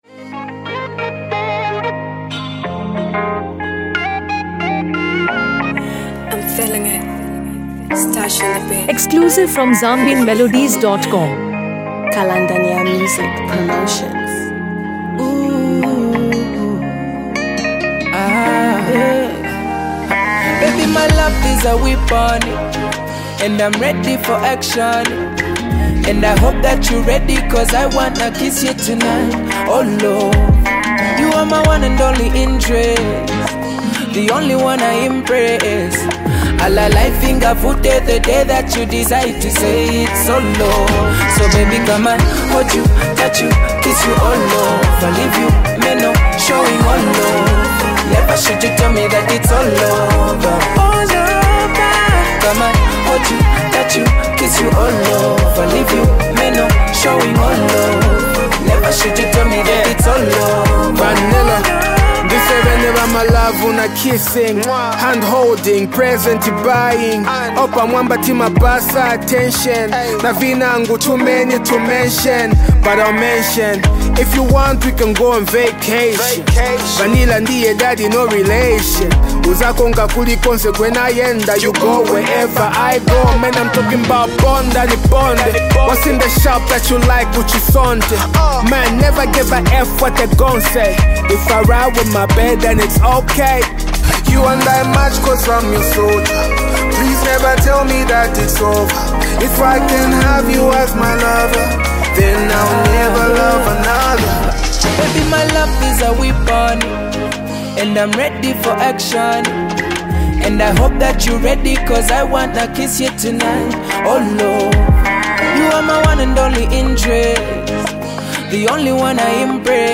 rap
whose smooth and emotional vocals add depth to any track